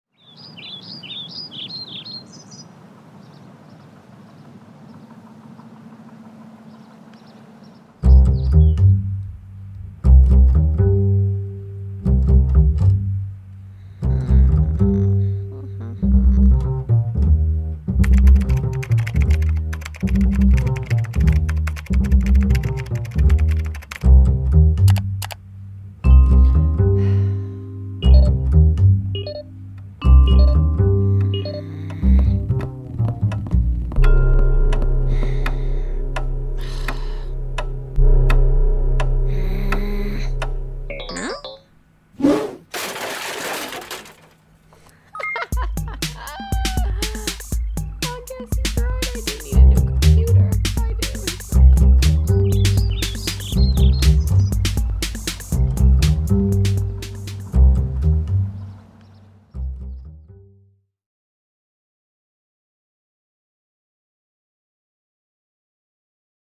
basic_soundscape.mp3